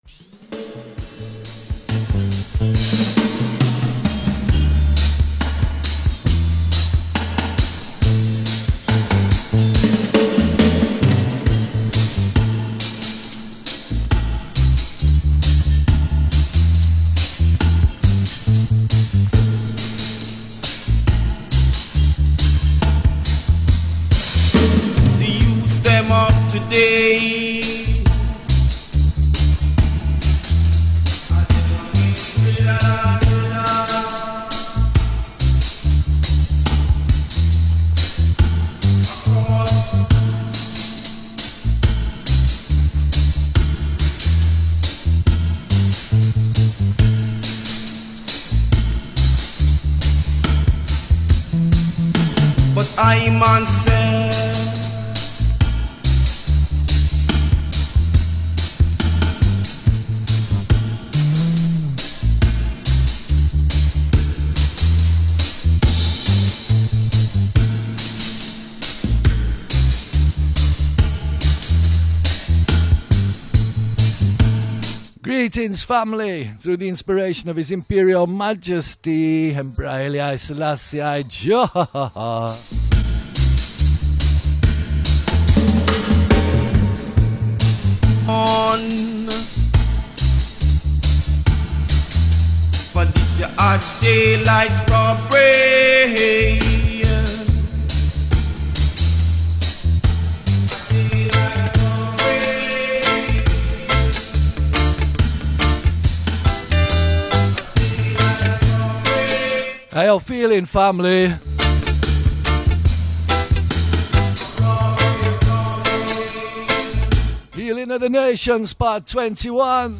Live recording of the Facebook Livestream